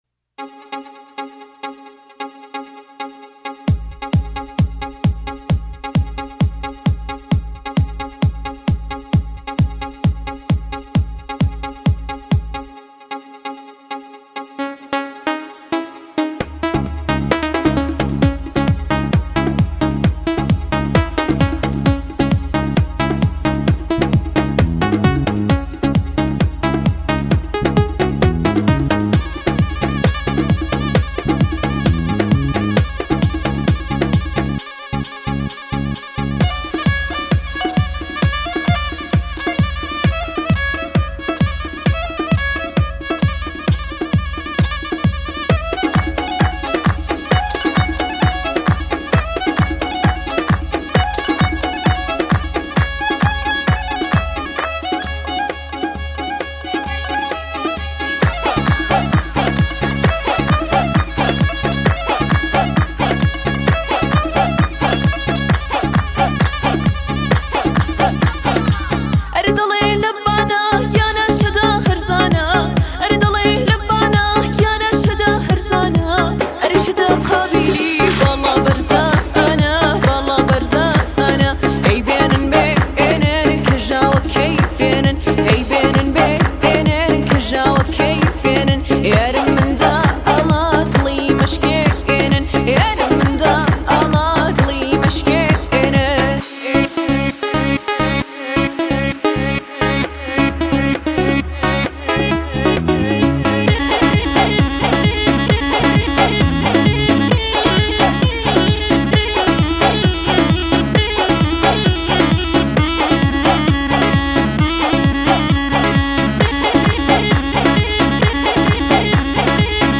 تو این حال و هوا یه اهنگ شاد گوش بدیم